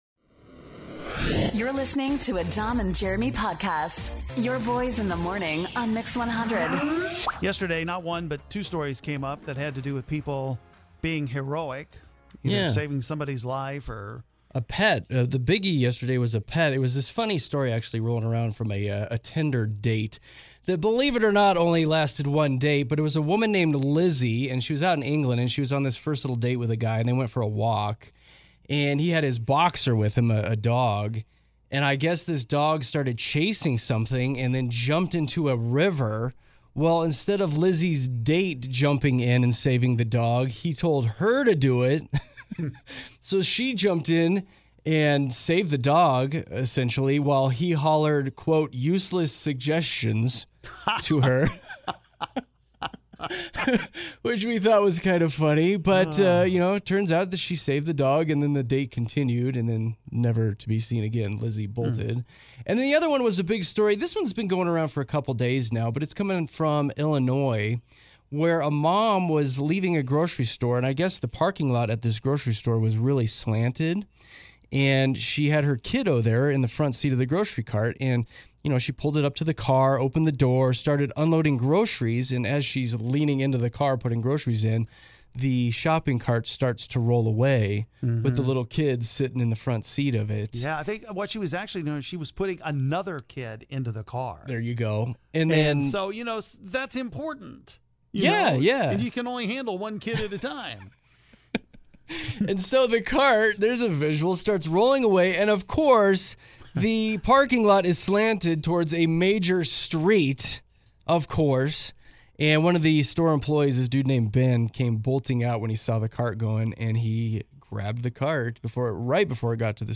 WOW - our phones exploded today with calls from people that have saved lives!